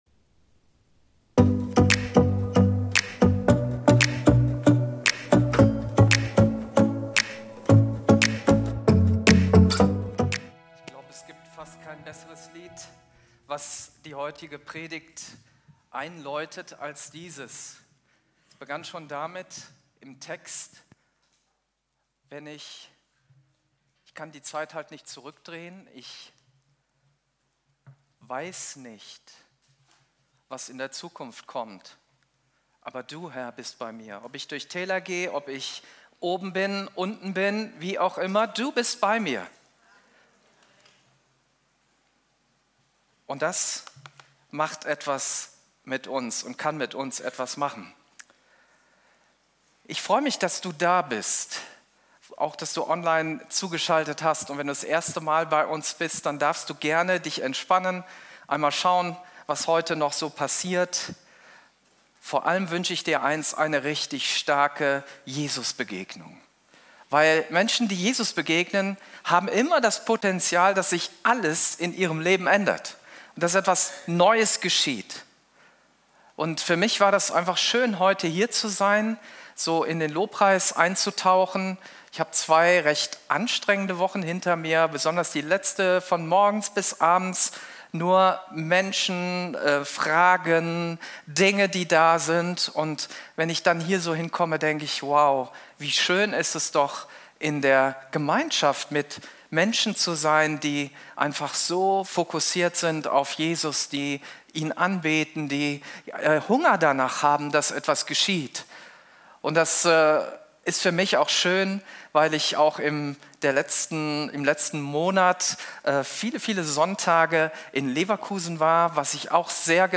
Video und MP3 Predigten
Kategorie: Sonntaggottesdienst Predigtserie: Fremde neue Welt